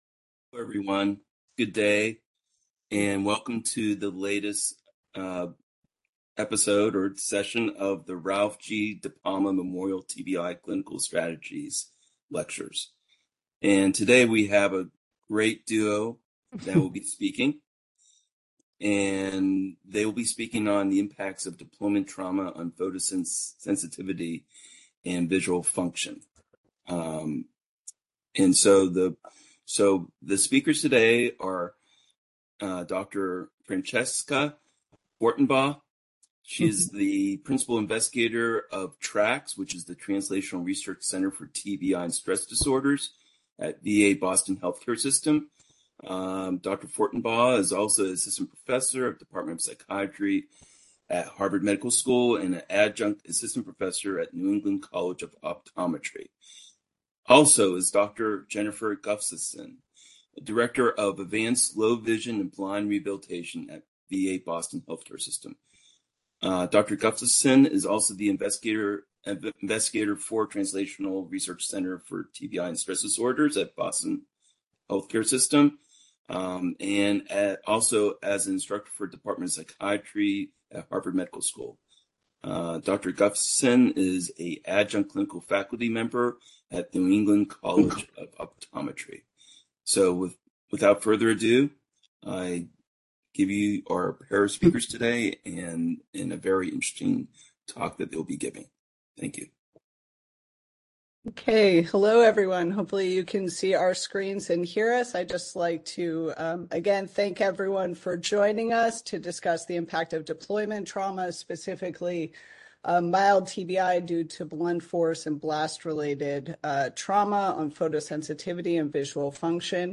FAAO Seminar date